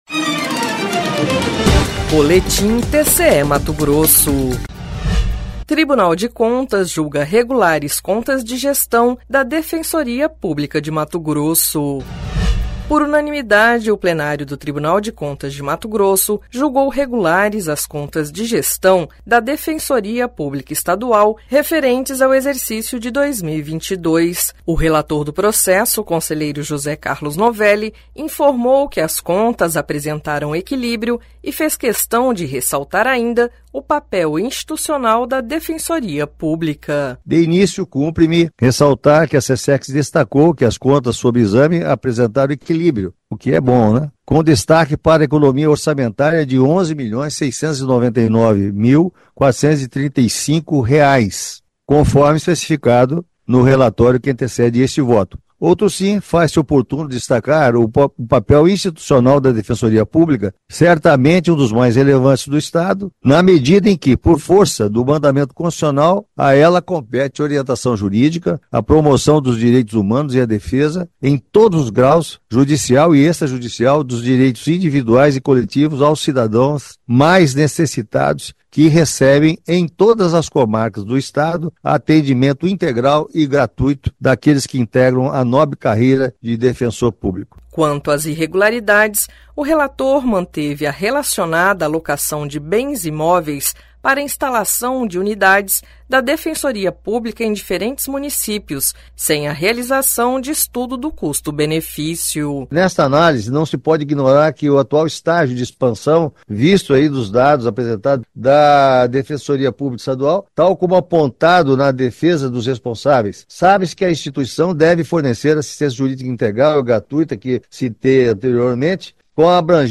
Sonora: José Carlos Novelli – conselheiro do TCE-MT
Sonora: Alisson Carvalho de Alencar - procurador-geral do MPC